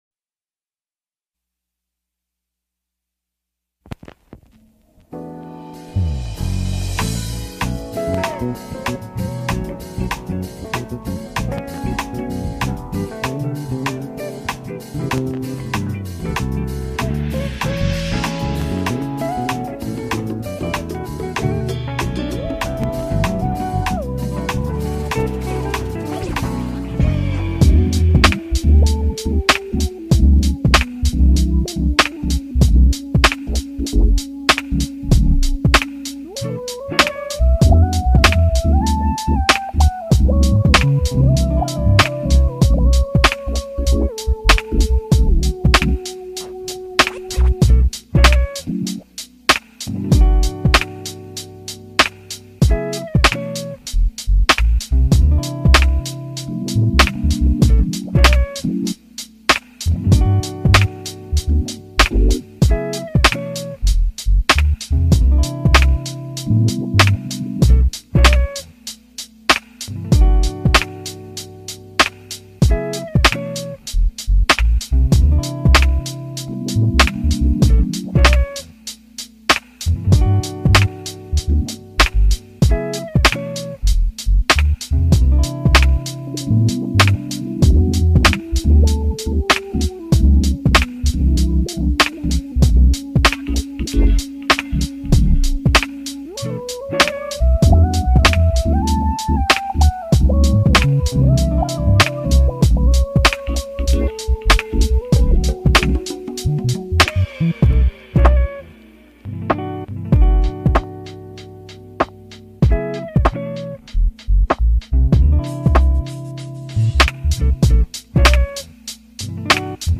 dreamy